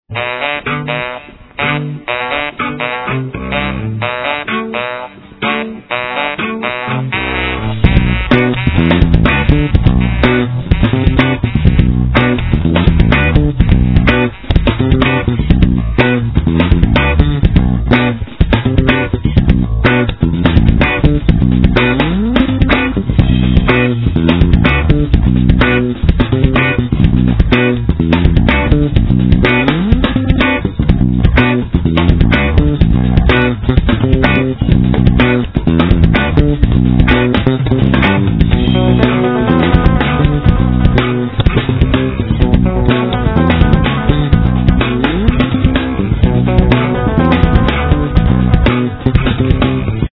Guitar
Drums
Sax
Bass